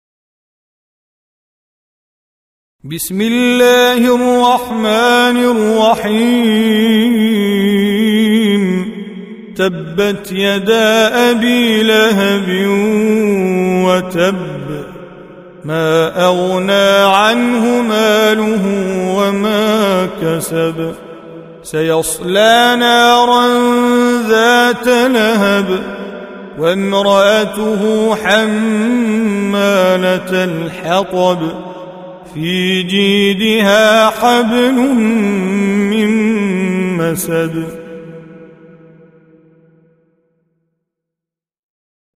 111. Surah Al-Masad سورة المسد Audio Quran Tajweed Recitation
Surah Sequence تتابع السورة Download Surah حمّل السورة Reciting Mujawwadah Audio for 111. Surah Al-Masad سورة المسد N.B *Surah Includes Al-Basmalah Reciters Sequents تتابع التلاوات Reciters Repeats تكرار التلاوات